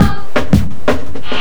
JUNGLE3-L.wav